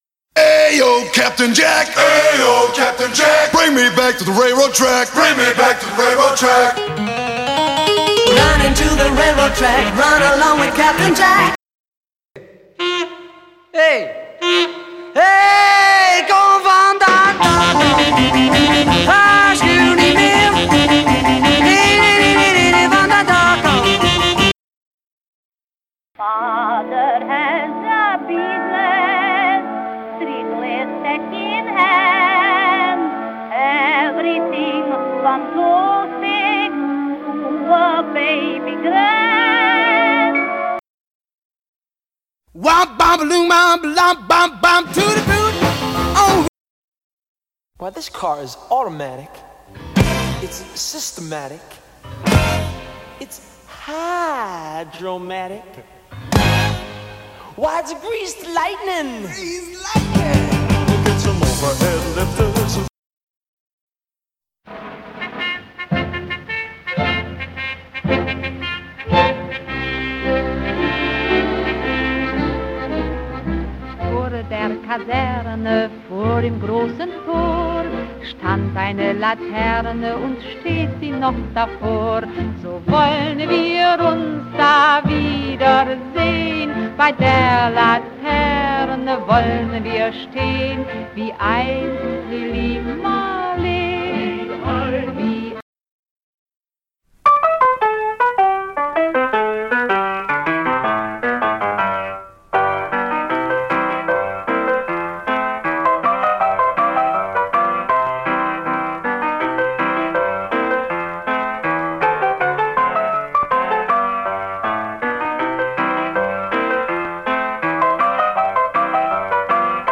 tekst gezongen